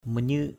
/mə-ɲɯ:ʔ/